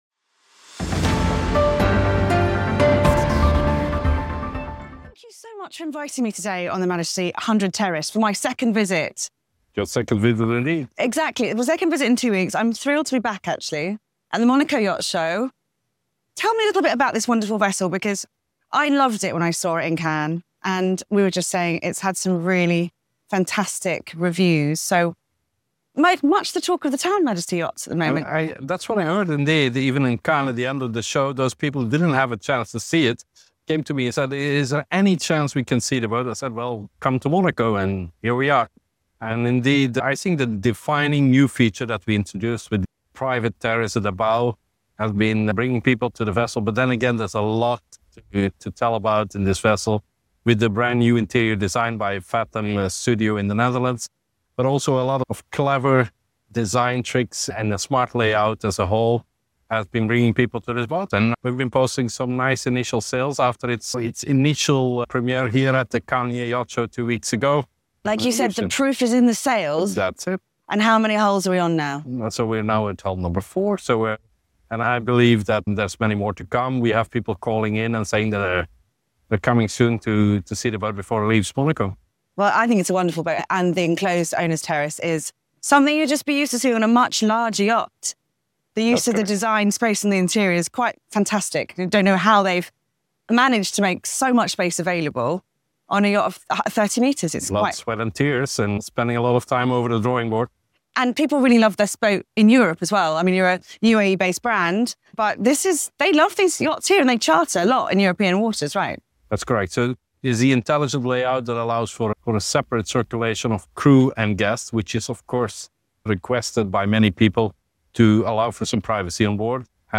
this exclusive interview explores: